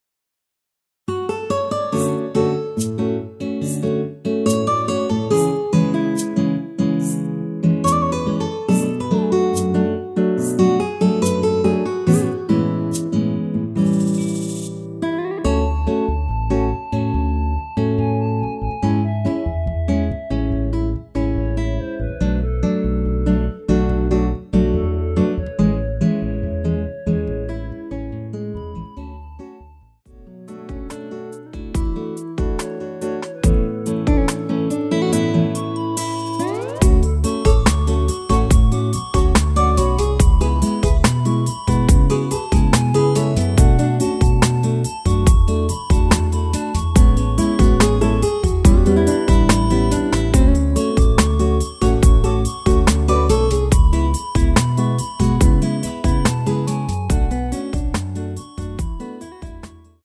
원키 멜로디 포함된 MR입니다.(미리듣기 확인)
앞부분30초, 뒷부분30초씩 편집해서 올려 드리고 있습니다.